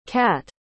cat.mp3